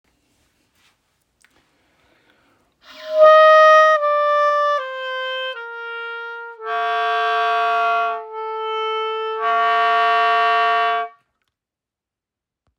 Ton spricht schlecht an, Nebengeräusch
Schnellere Läufe sind dann aber nicht mehr möglich, sobald das H1 kommt, kommt auch beim schnellen Spiel ein kleines Nebengeräusch zum Beginn des Tons. Wenn man es provoziert, kommt ein Röhren.